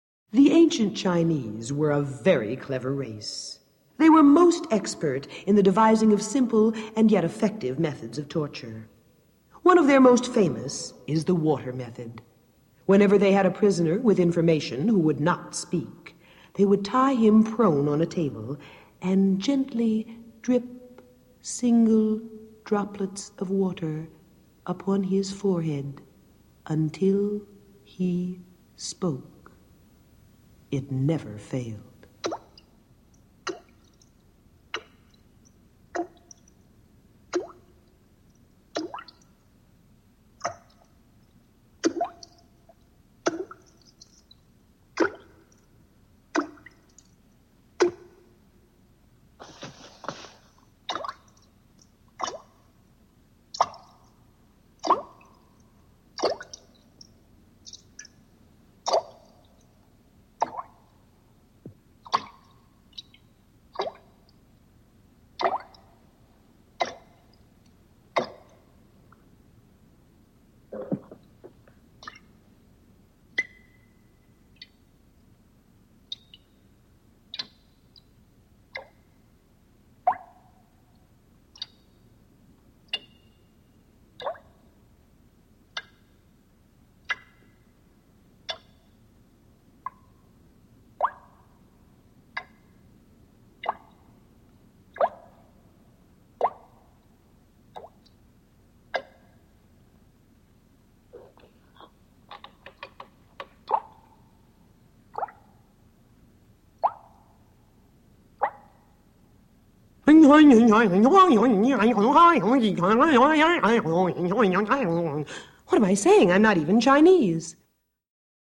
Index of /Sounds/Halloween/Haunted House-64
08 The Chinese Water Torture.mp3